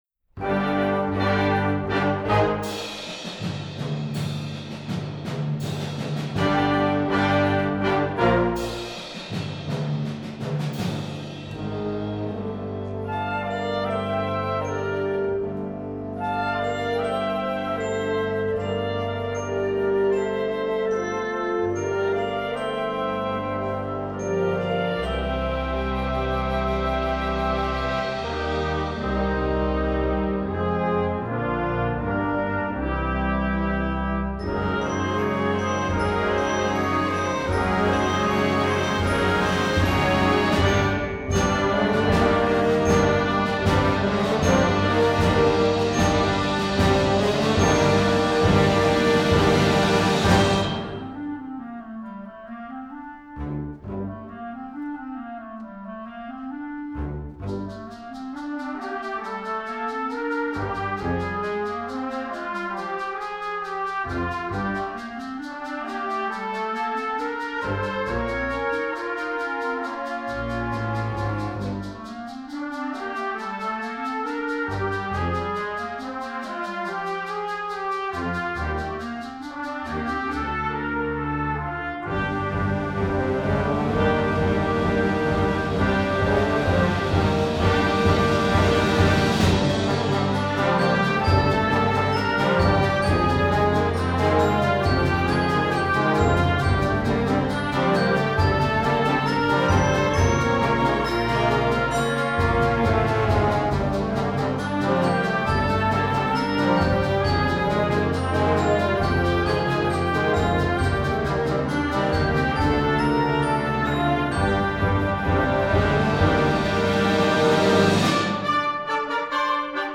Gattung: Jugendwerk
3:03 Minuten Besetzung: Blasorchester PDF